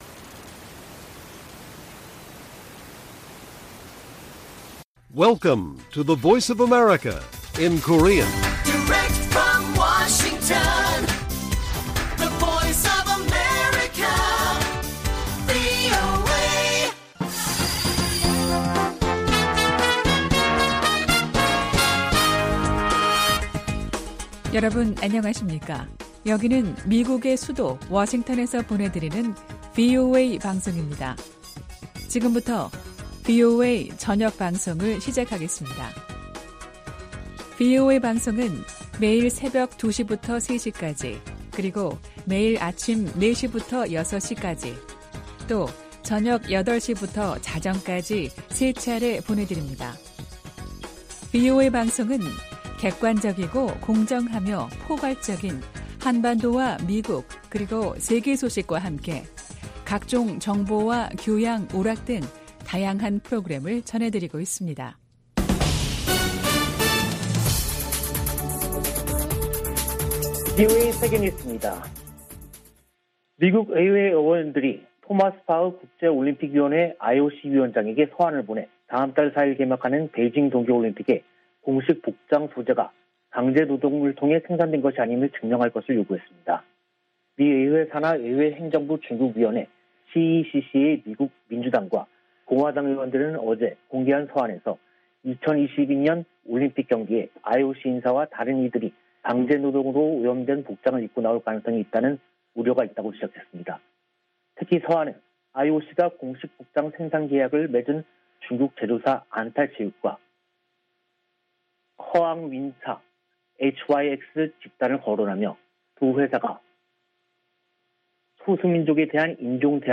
VOA 한국어 간판 뉴스 프로그램 '뉴스 투데이', 2022년 1월 13일 1부 방송입니다. 미국이 북한 탄도미사일 발사에 대응해 유엔에서 추가 제재를 추진합니다. 미국 정부가 북한 미사일 관련 물품을 조달한 북한 국적자 6명과 러시아인 등을 제재했습니다. 북한이 김정은 국무위원장 참관 아래 극초음속 미사일 시험발사 성공을 발표하면서 미-북 간 갈등이 고조되고 있습니다.